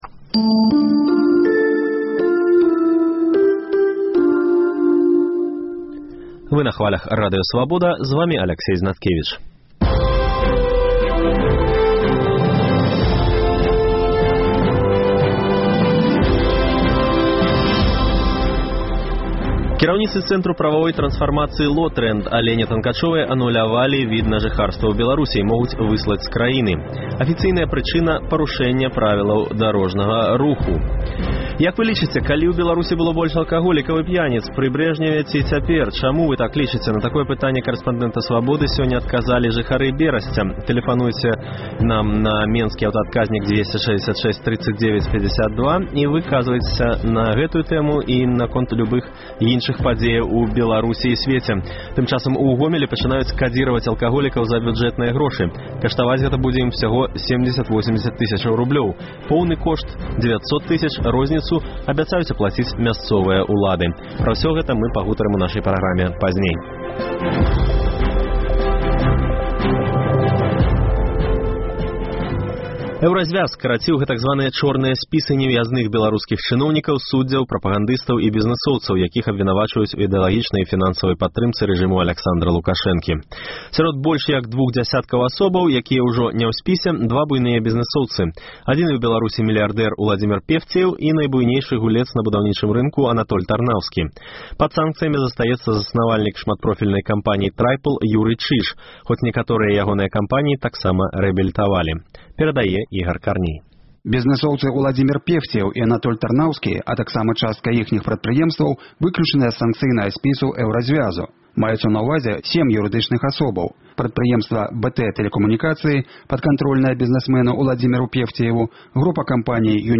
Паведамленьні нашых карэспандэнтаў, госьці ў жывым эфіры, званкі слухачоў, апытаньні ў гарадах і мястэчках Беларусі.